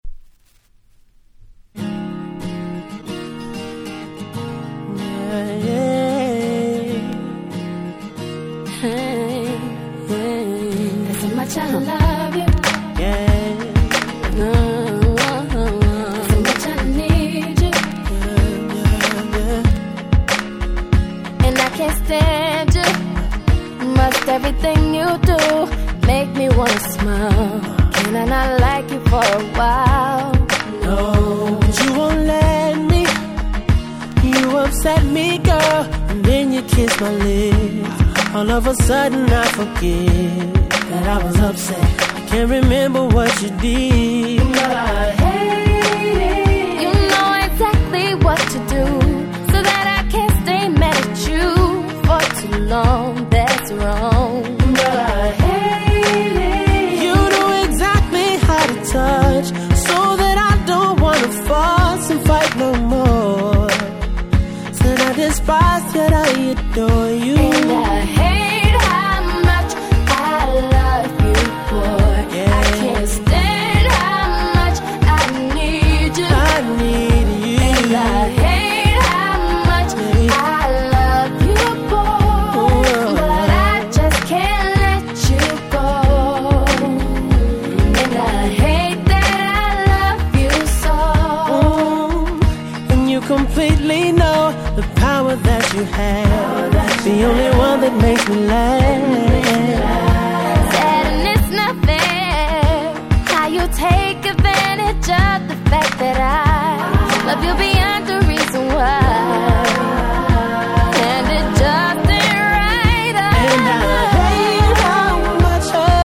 07' Smash Hit R&B !!